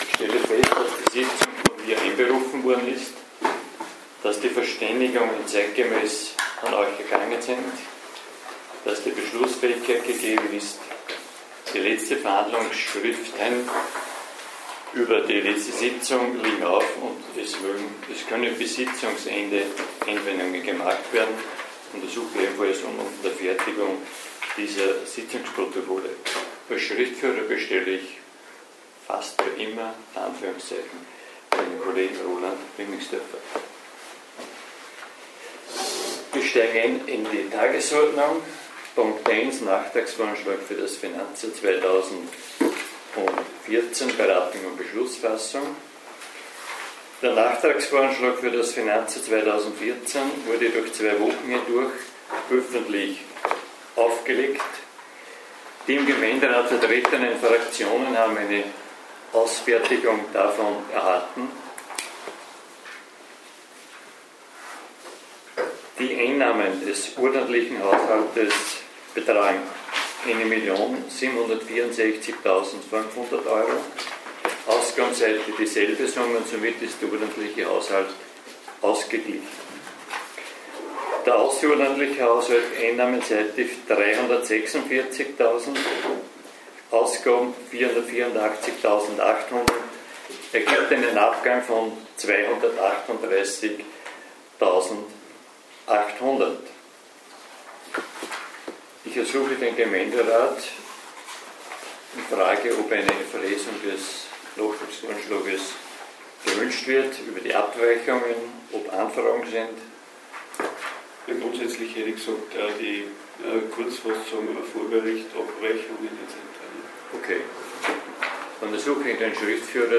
Gemeinderatssitzung - 20.03.2014 Verständigung: Download Verhandlungsschrift: Download Audiomitschnitt: Download (inkl. Wortmeldung der Grünen durch Herrn Ing. Heinz Plohberger nach Ende der Sitzung )